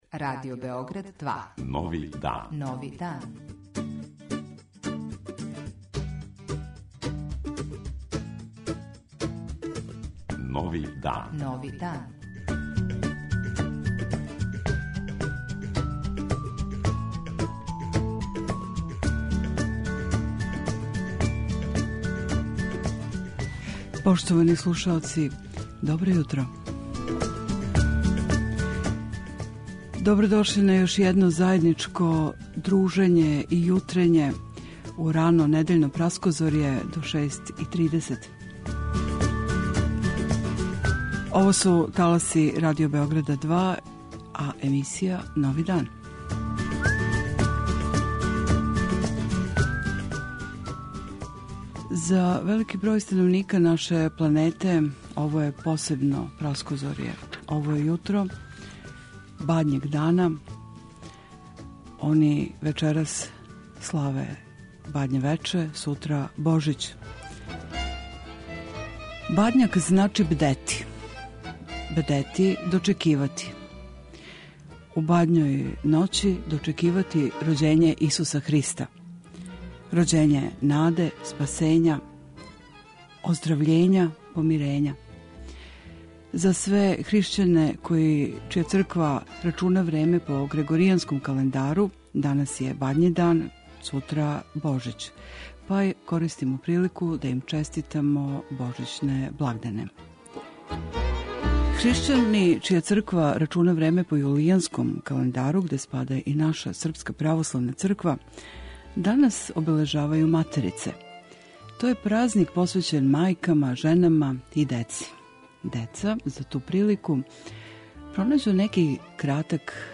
Шта је суштина Бадњег дана и Божића и која је улога жене, мајке, у данашњем времену - говори монсињор Станислав Хочевар, београдски надбискуп и метрополит. Господин Хочевар објашњава и у чему је разлика између мисе и литургије и зашто се богослужи у поноћ, али сећа се и радости божићних празника када је и сам био дете.
Избор музике